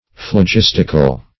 Phlogistical \Phlo*gis"tic*al\, a.
phlogistical.mp3